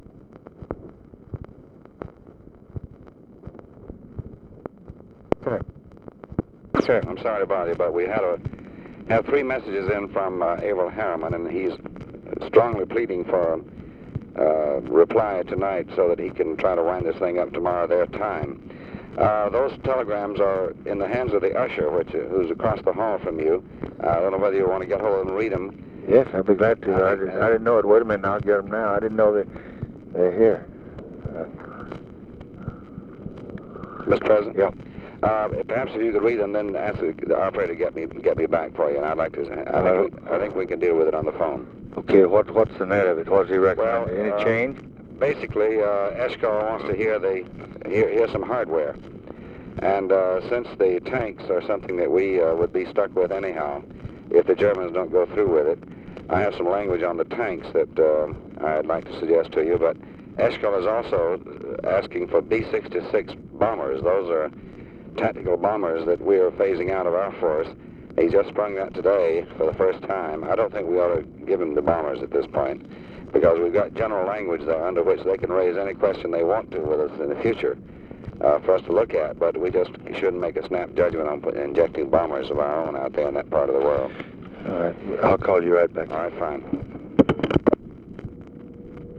Conversation with DEAN RUSK, February 28, 1965
Secret White House Tapes